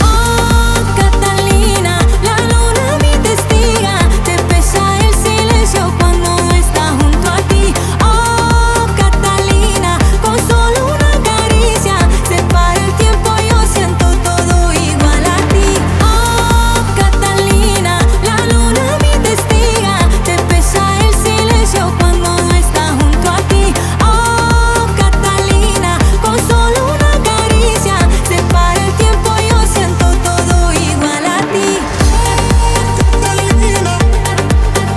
2025-07-04 Жанр: Танцевальные Длительность